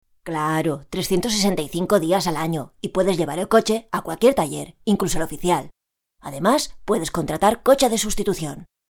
Para la realización de este promocional de televisión  nos pidieron las voces de varios muñecos, pero el cliente no estaba seguro de si quería una voz de mujer ,